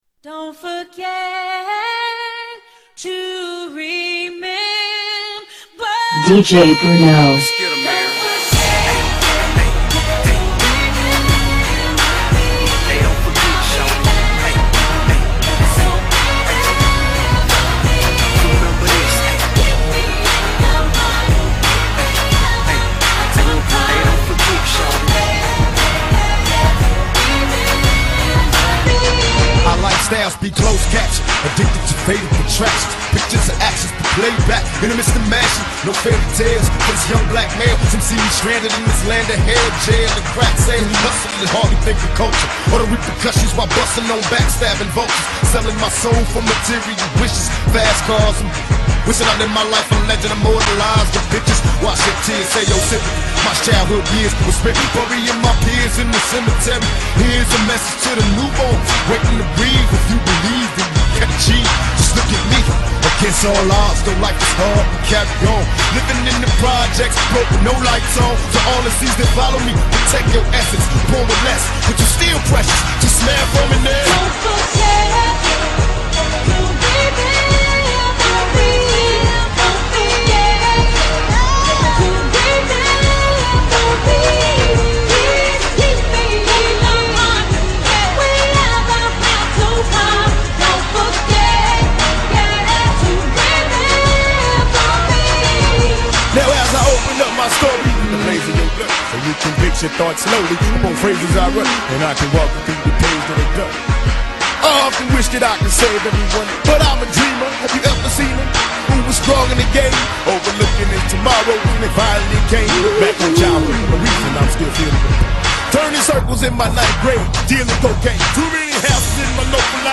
Mash ups songs